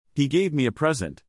a present /PRE-sent/ (a gift) vs. to present  /pre-SENT/ (give something formally)